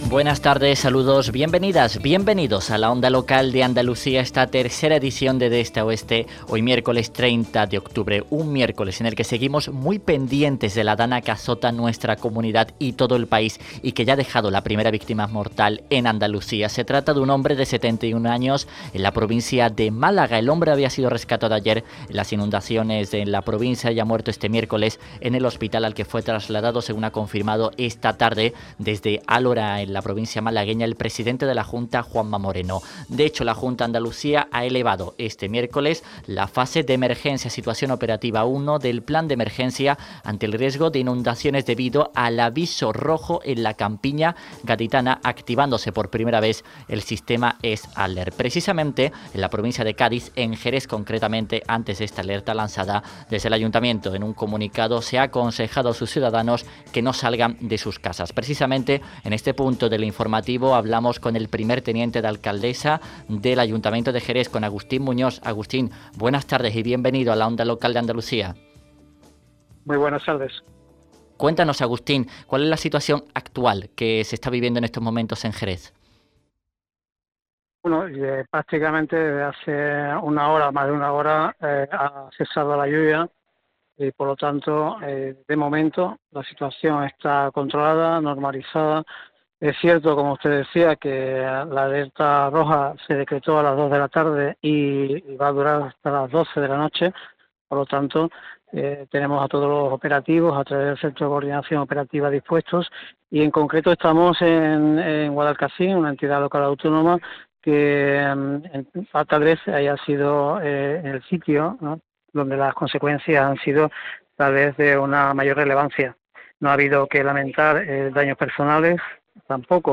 Hablamos con Agustín Muñoz, 1º teniente de alcaldesa de Jerez